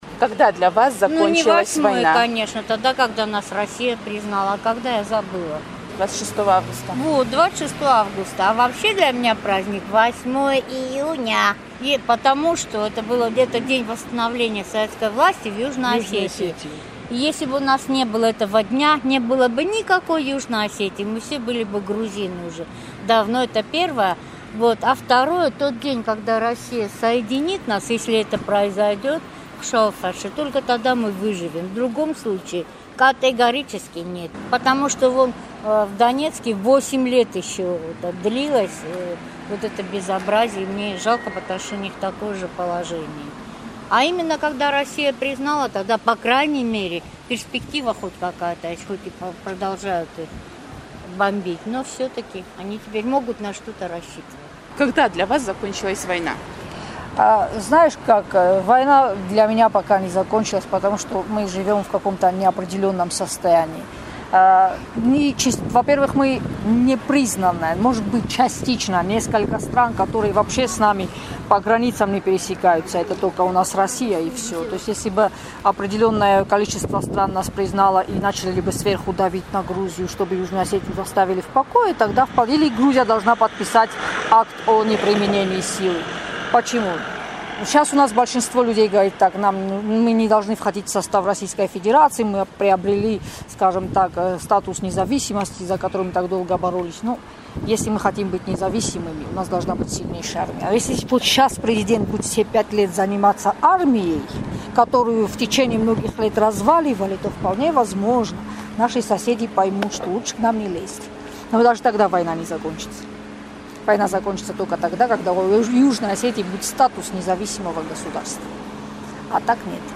«Эхо Кавказа» спросило у горожан, когда для них закончилась августовская война?